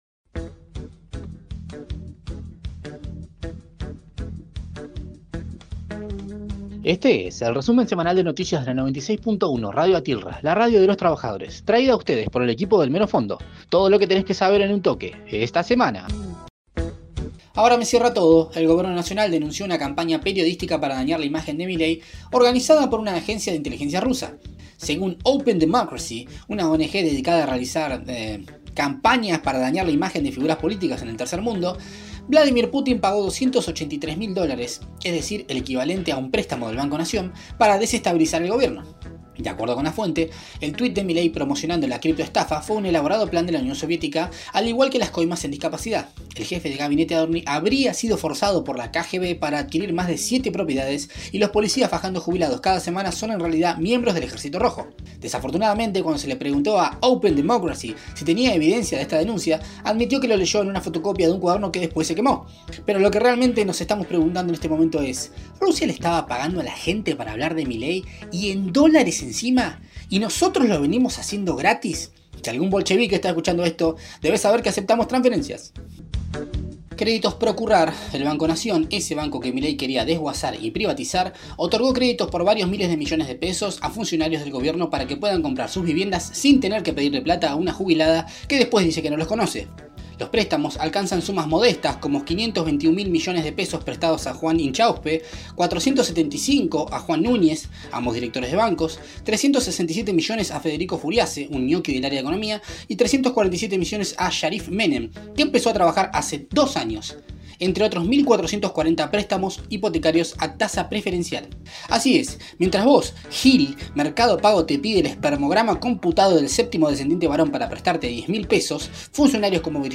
Flash Informativo